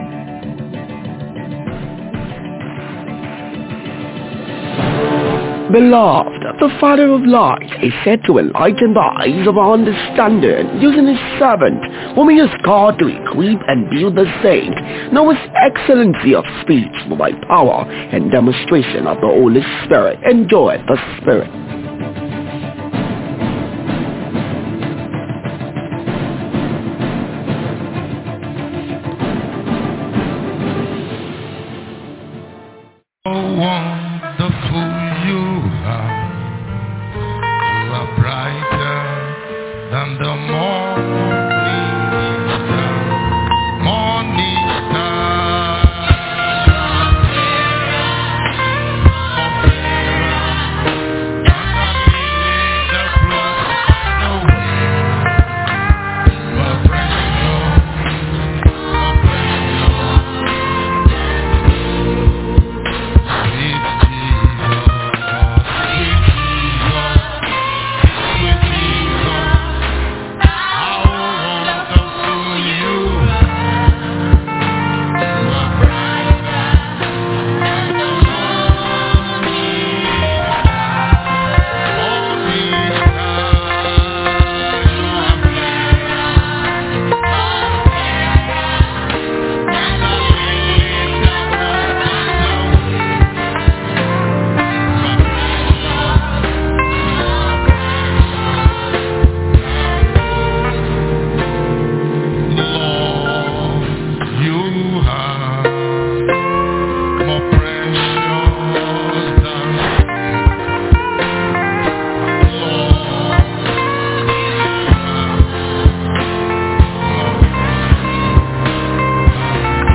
Youth Convention Day 3 Sunday Message- Spiritual Sensitivity in handling Divine Timing
Youth-Convention-Day-3-Sunday-Message-Spiritual-Sensitivity-in-handling-Divine-Timing.mp3